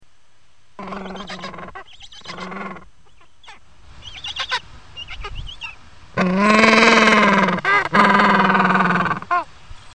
Manchot papou. Ile de Croÿ.